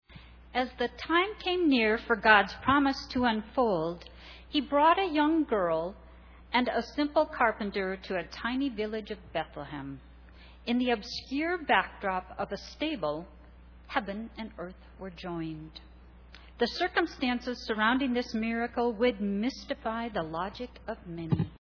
Sunday Service
15Narration.mp3